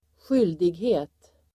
Uttal: [²sj'yl:dighe:t]